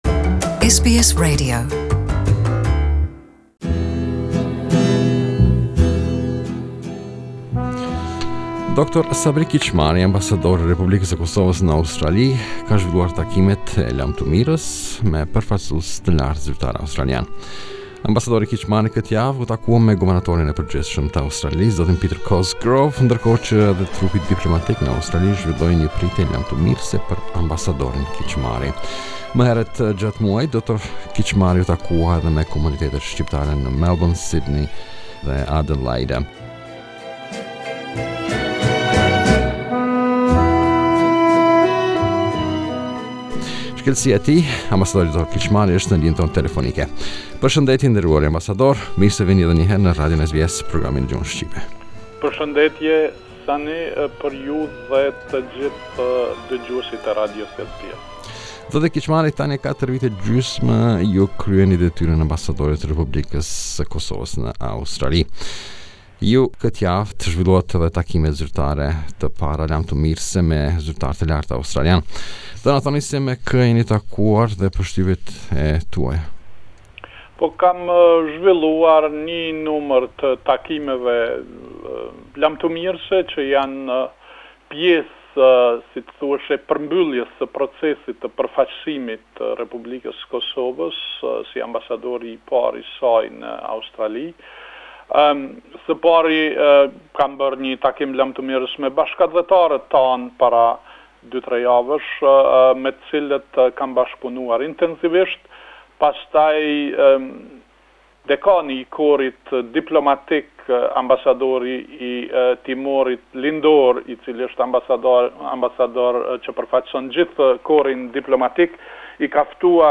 Interview with Dr Sabri Kiqmari
This is an interview with the Ambasador of the Republic of Kosovo in Australia.